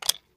click.ogg